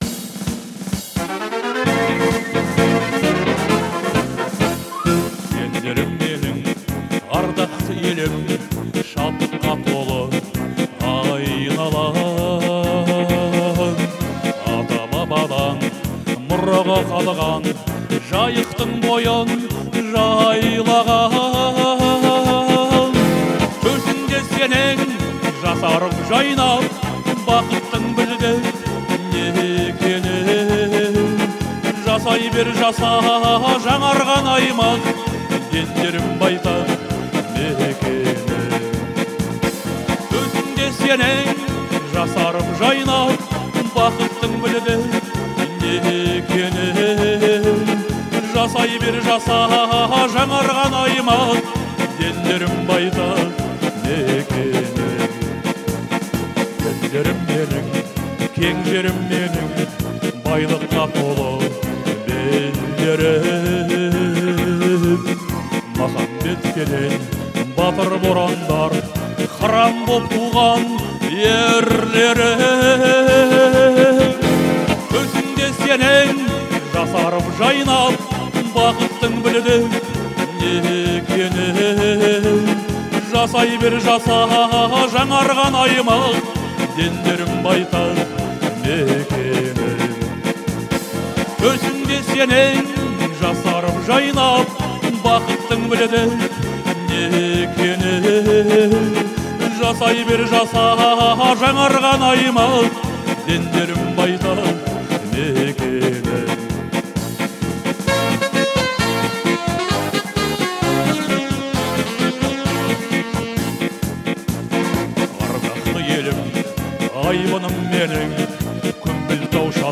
это проникновенная песня в жанре казахской народной музыки
искренность и эмоциональность вокала